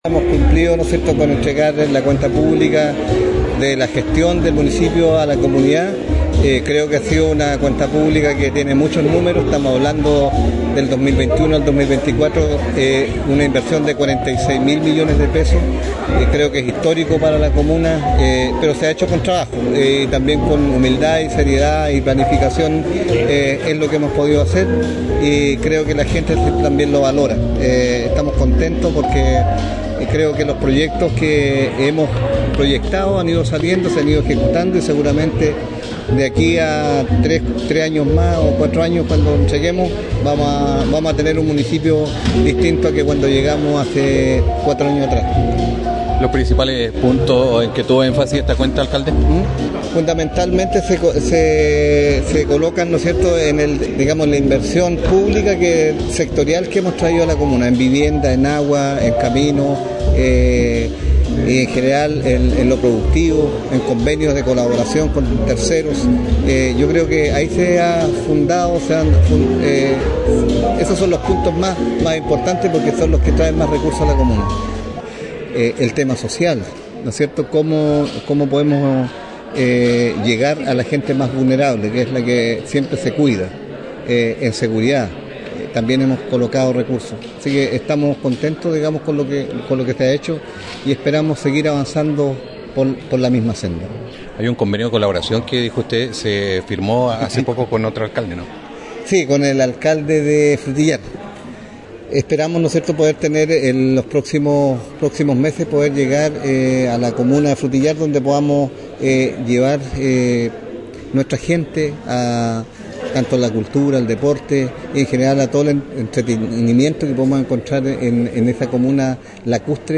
Al finalizar la ceremonia, el alcalde Juan Rocha conversó con «Portal Radio», donde profundizó en los logros alcanzados durante el 2024 y en las proyecciones de su gestión para los próximos meses, reafirmando su compromiso con el bienestar de los vecinos y vecinas de Lanco, Malalhue y el sector rural de la comuna.